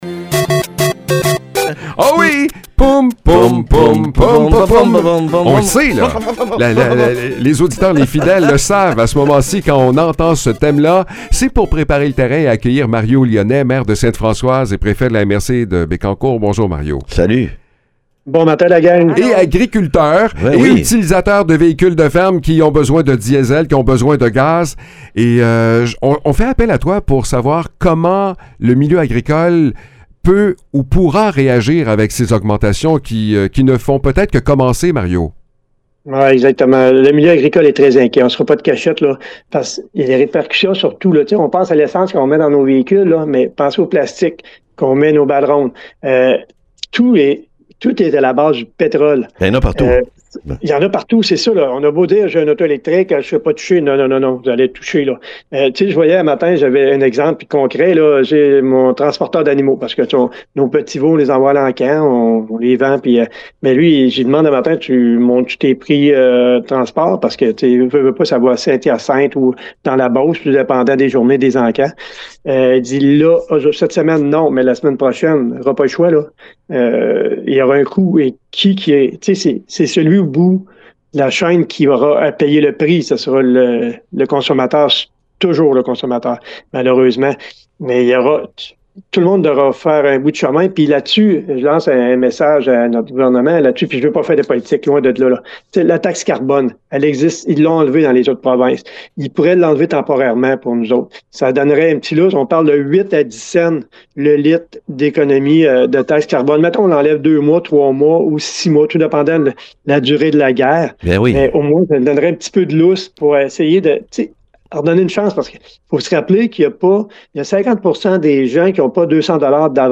Mario Lyonnais, maire de Sainte-Françoise et préfet de la MRC de Bécancour, est aussi agriculteur. Il nous explique comment le milieu agricole devra composer avec la hausse du prix de l’essence.